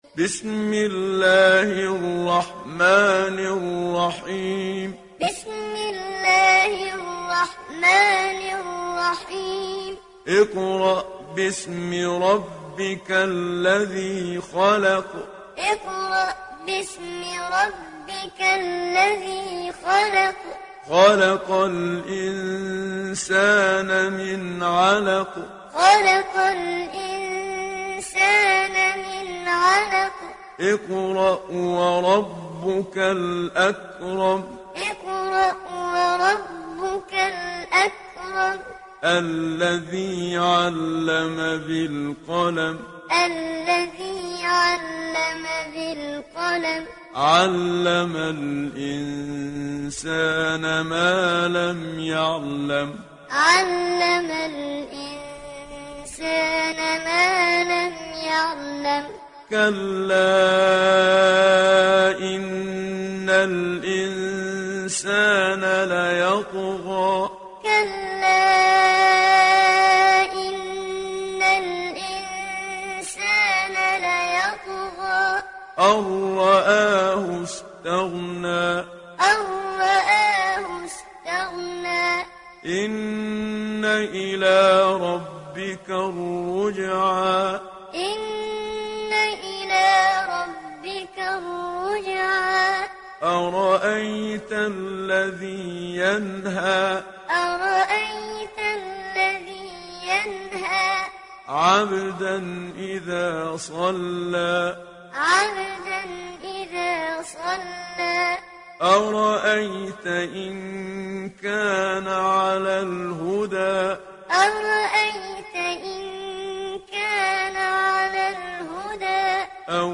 Alak Suresi İndir mp3 Muhammad Siddiq Minshawi Muallim Riwayat Hafs an Asim, Kurani indirin ve mp3 tam doğrudan bağlantılar dinle
İndir Alak Suresi Muhammad Siddiq Minshawi Muallim